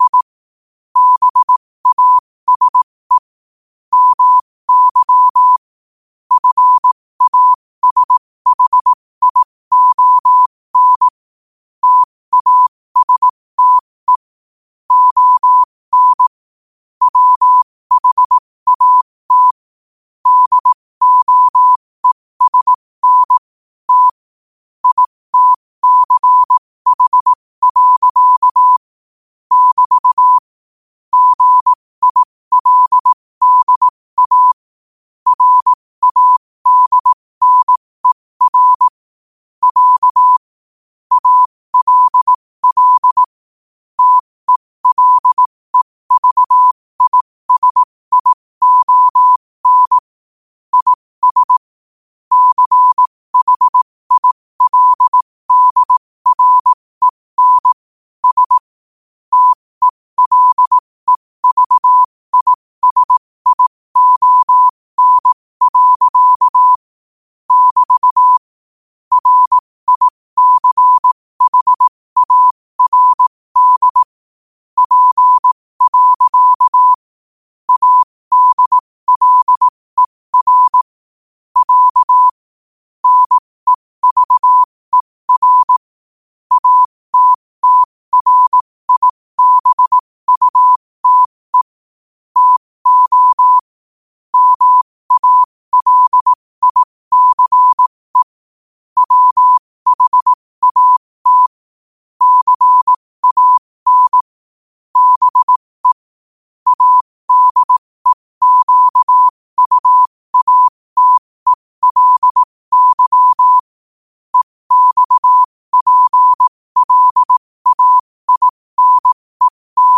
QOTD podcast provides daily podcasts of quotations in morse code, useful for studying morse code
New quotes every day in morse code at 15 Words per minute.
Quotes for Fri, 06 Jun 2025 in Morse Code at 15 words per minute.